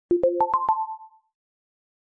点击4.wav